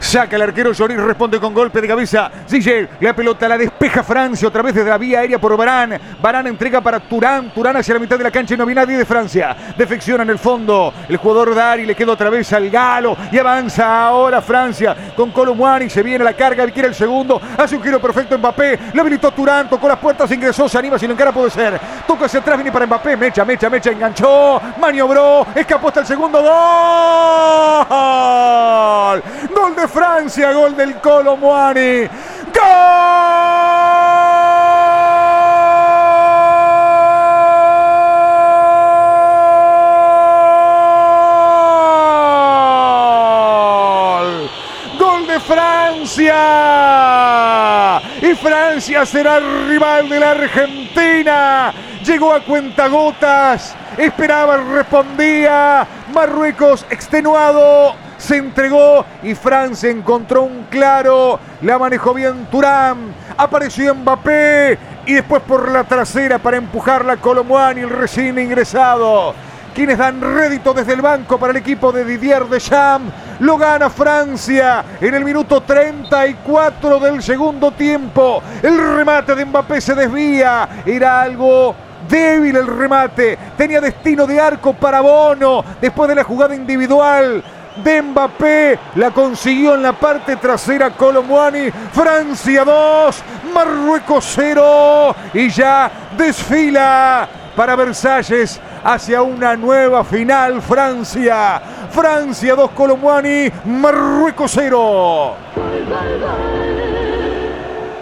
Relato